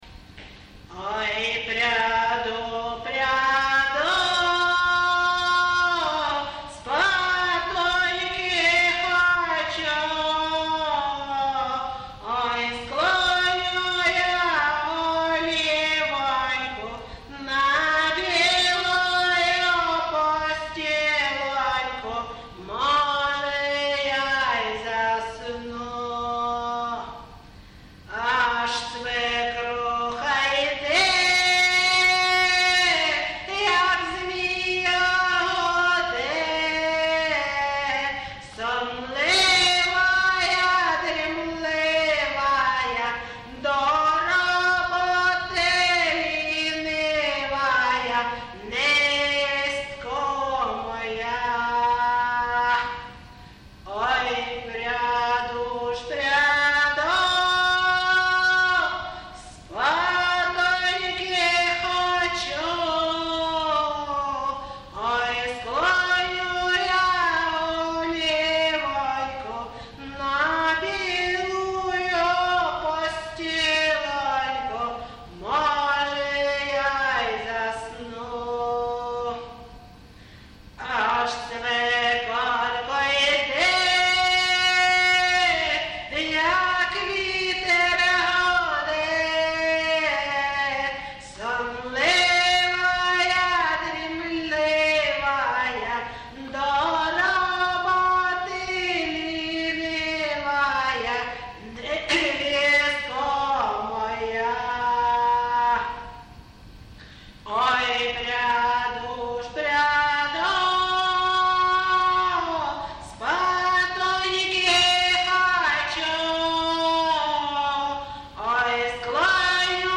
ЖанрПісні з особистого та родинного життя
Місце записус. Ковалівка, Миргородський район, Полтавська обл., Україна, Полтавщина